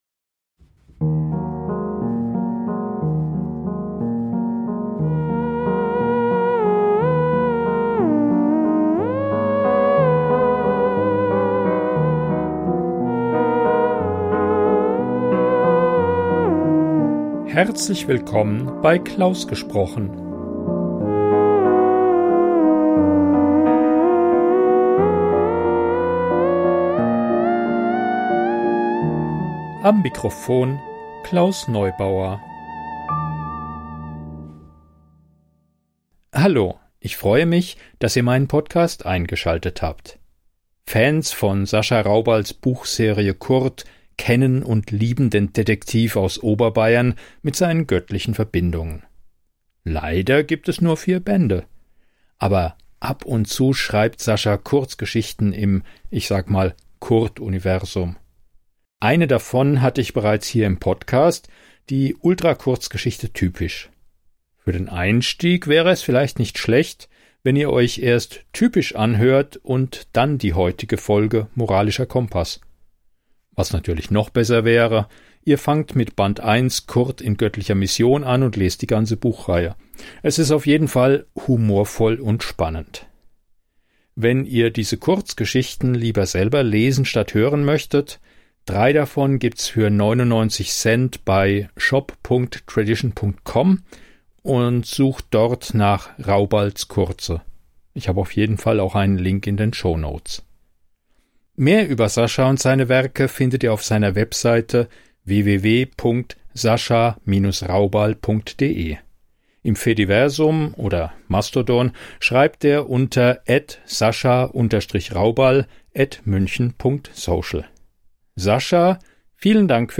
Privatdetektiv Kurt Odensen ermittelt. Humorvoller Krimi von Sascha Raubal.